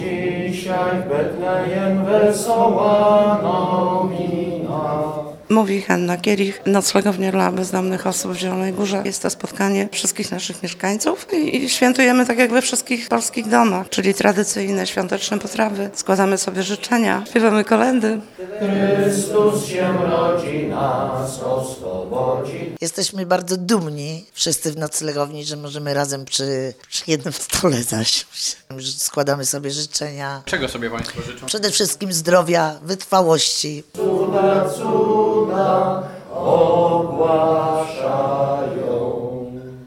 Było także kolędowanie, łamanie się opłatkiem i życzenia: